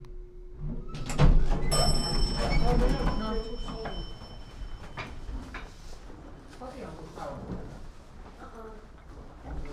130517 elevator door open ding talking hotel NYC
Category 🗣 Voices
chime ding elevator sound effect free sound royalty free Voices